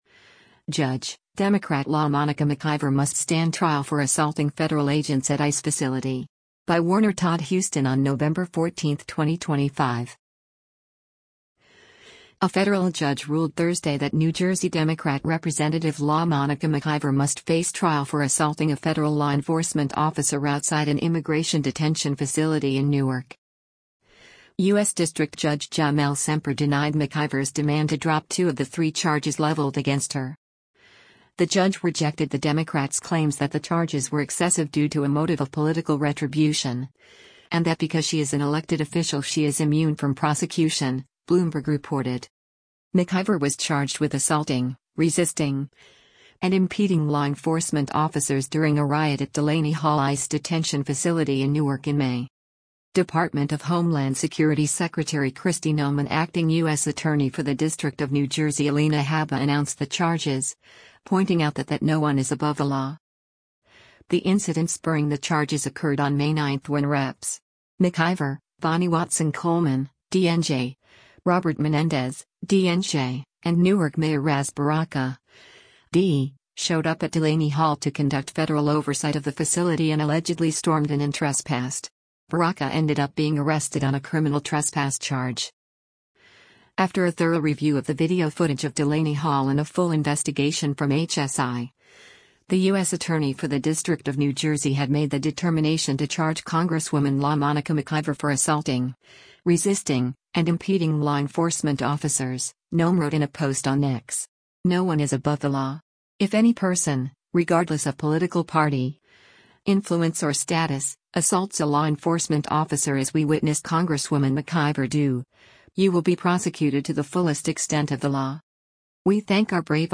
Representative LaMonica McIver, a Democrat from New Jersey, speaks to members of the media